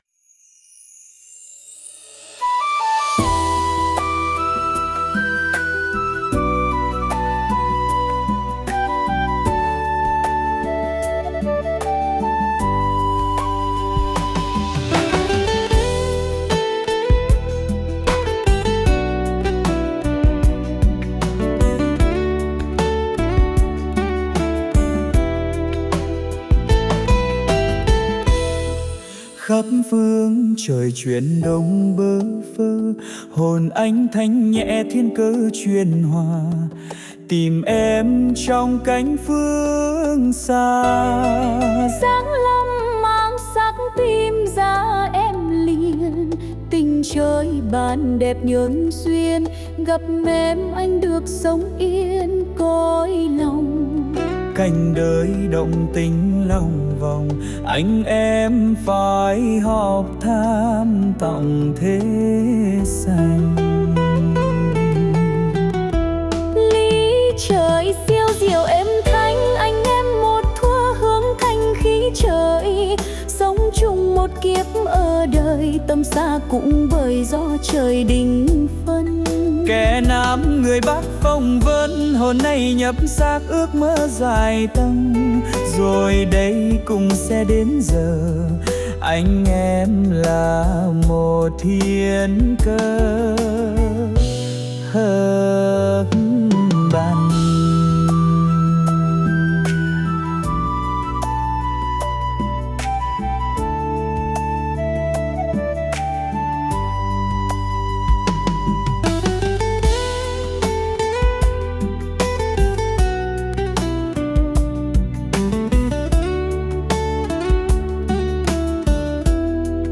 176-Tim-em-01-nam-nu.mp3